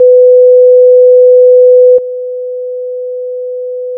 《基準壁》からの音 2秒 →《基準壁+ノイズクリア》からの音 2秒
- 固体伝播音の場合 -
( スピーカーを壁に直付け固定して測定 )